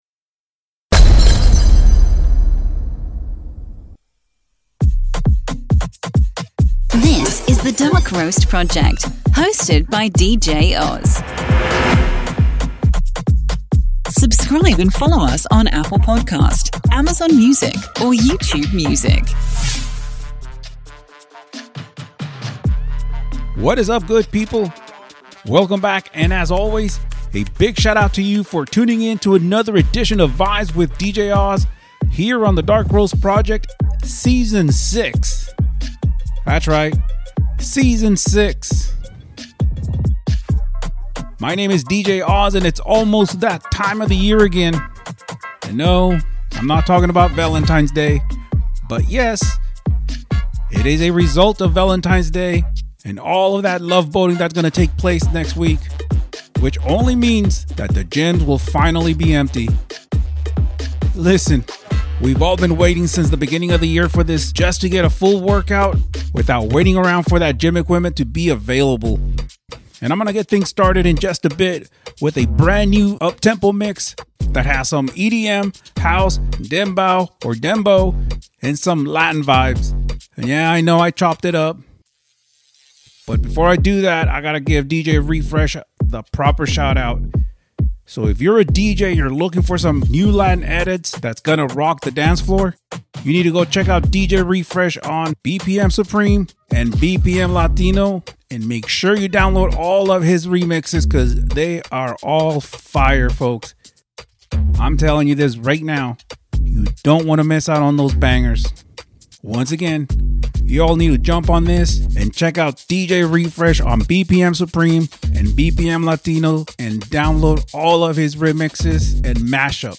A HIGH ENERGY WORKOUT MIX...